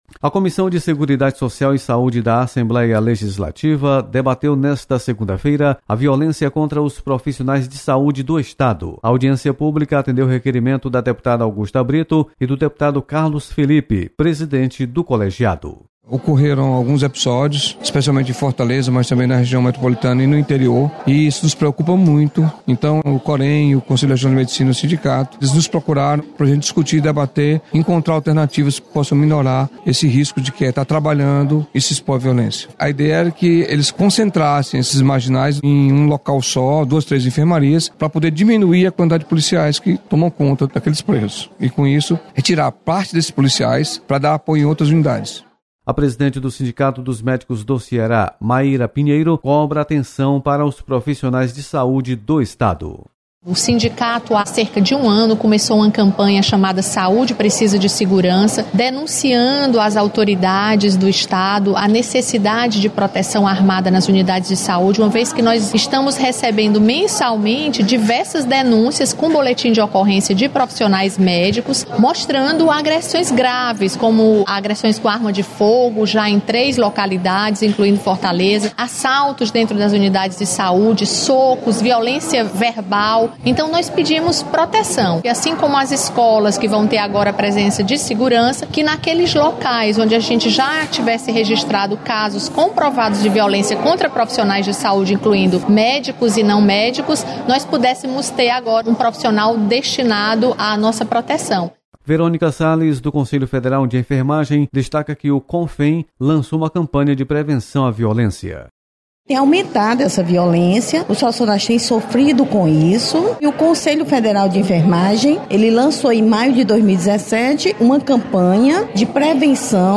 Você está aqui: Início Comunicação Rádio FM Assembleia Notícias Audiência